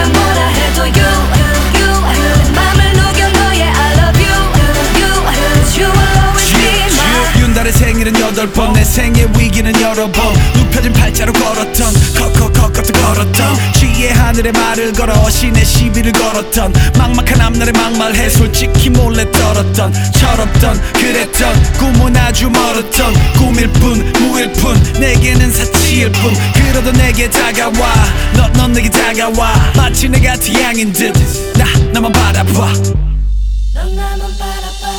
Жанр: Хип-Хоп / Рэп / Поп музыка
K-Pop, Pop, Hip-Hop, Rap